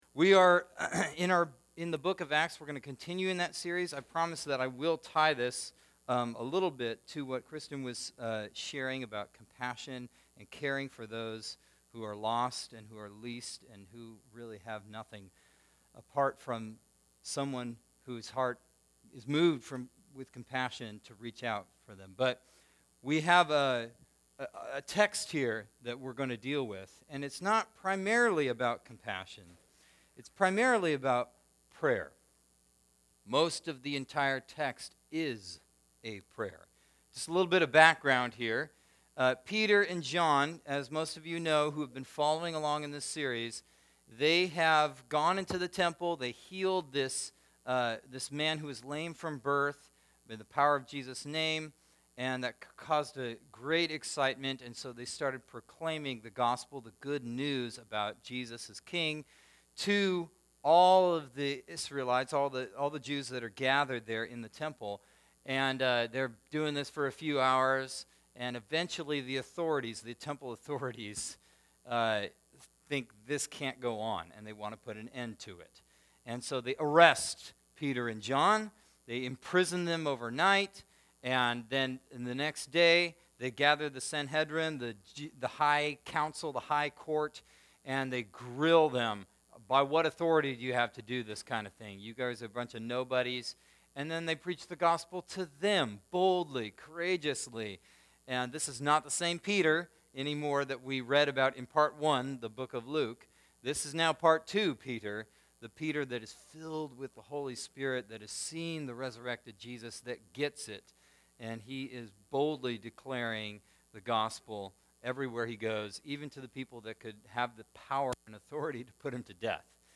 Sermons - Wapato Valley Church